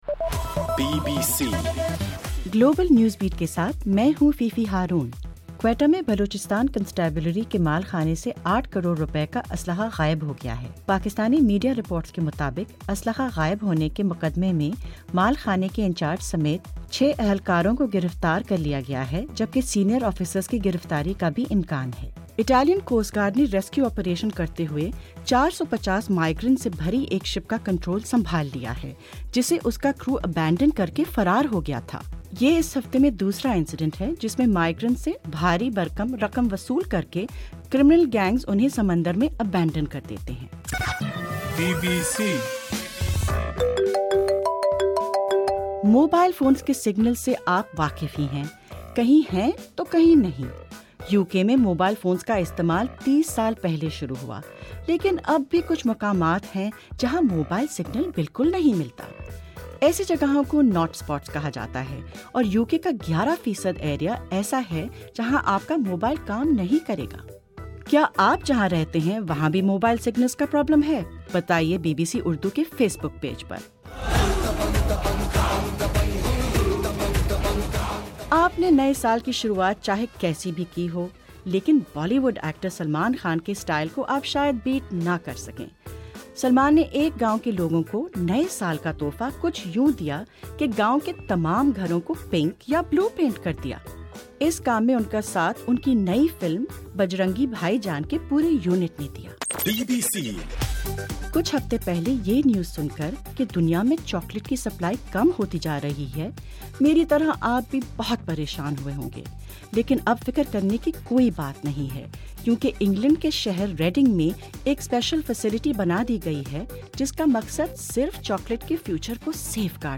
جنوری 02: رات 10 بجے کا گلوبل نیوز بیٹ بُلیٹن